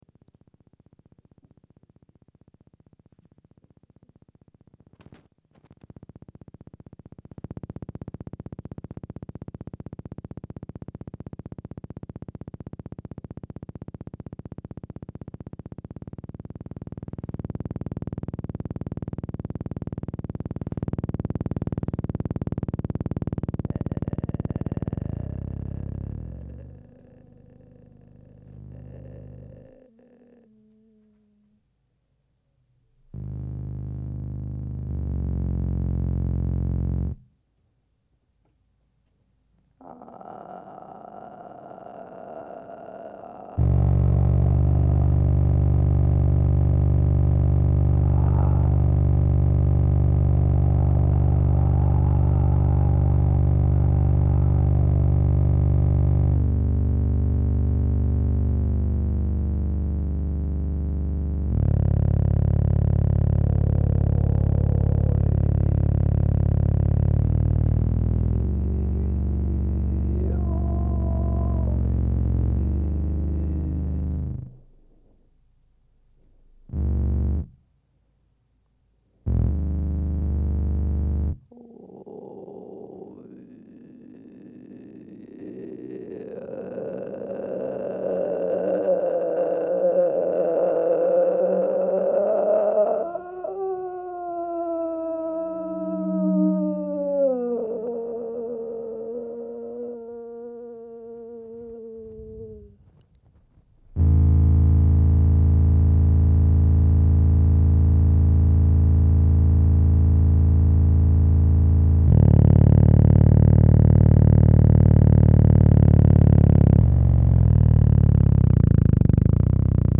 Venue: Arena Bar, Vienna
Auszüge aus eine Live-Performance "
ein Dialog mit no-input-mixer und Schafen
auszug_lounge_music.mp3